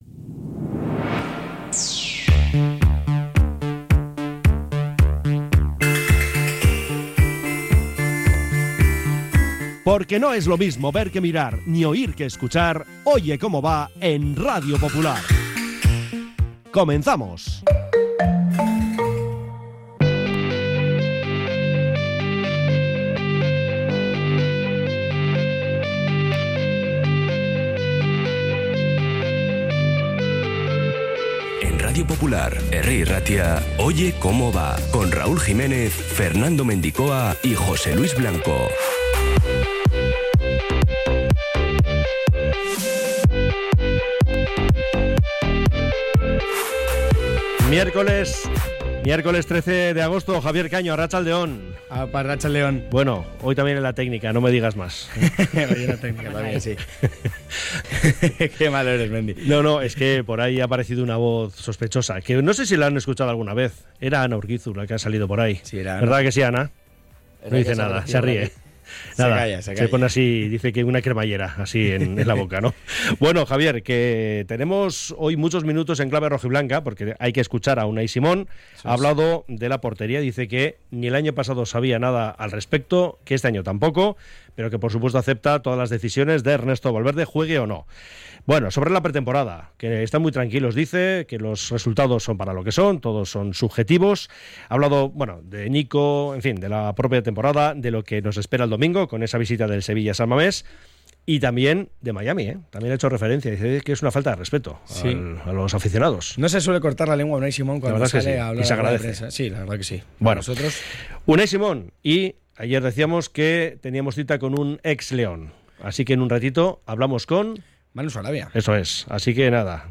Oye Cómo Va 13-08-25 | Rueda de prensa de Unai Simón